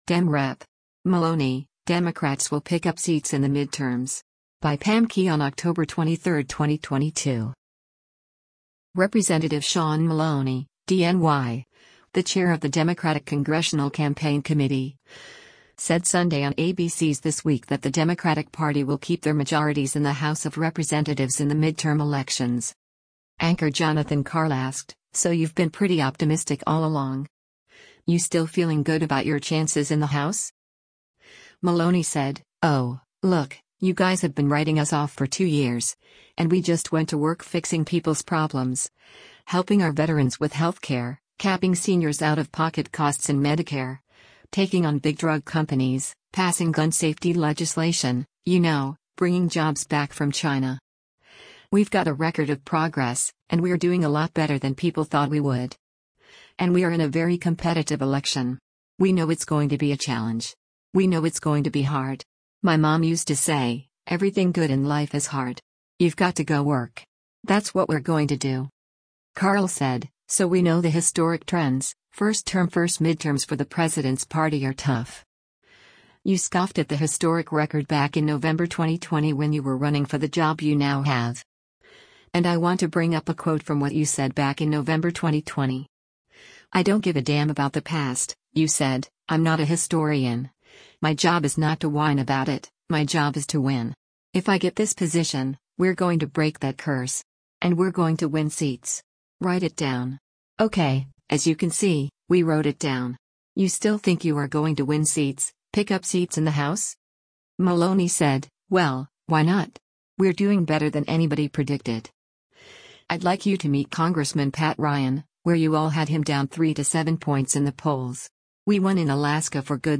Representative Sean Maloney (D-NY), the chair of the Democratic Congressional Campaign Committee, said Sunday on ABC’s “This Week” that the Democratic Party will keep their majorities in the House of Representatives in the midterm elections.